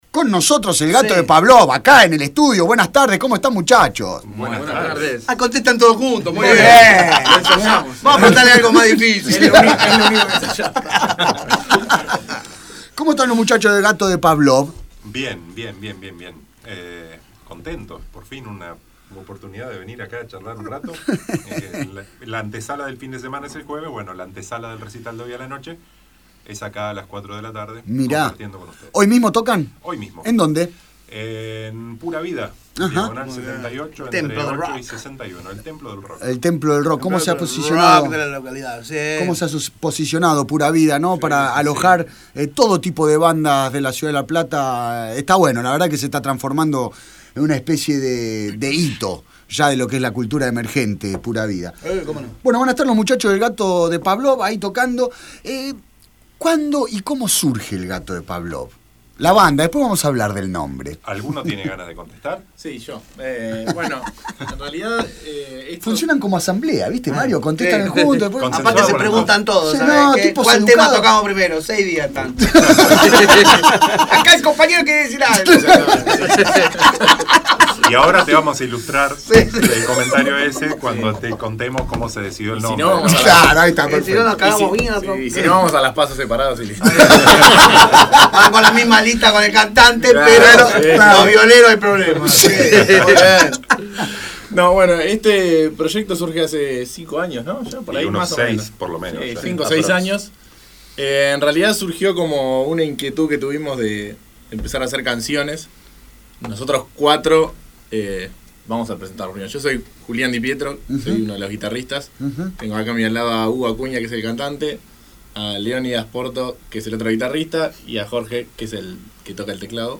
Voz
Teclitas
Guitarra
tocó en vivo